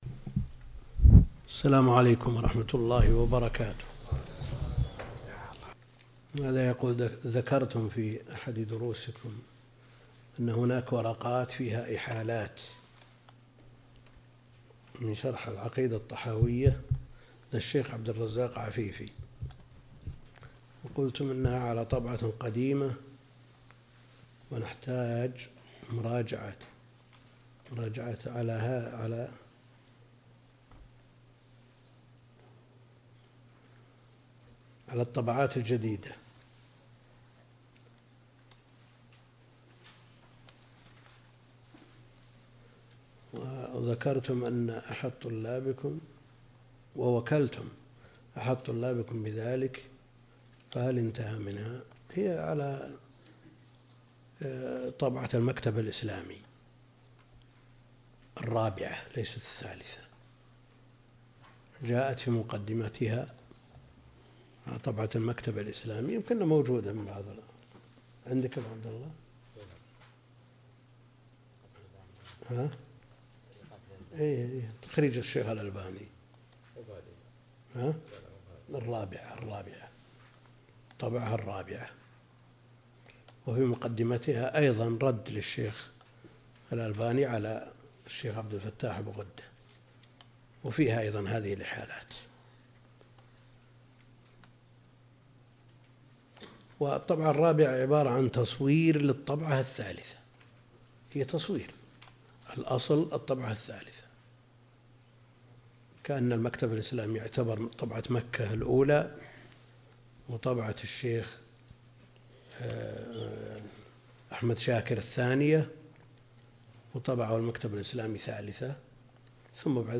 الدرس (57) شرح العقيدة الطحاوية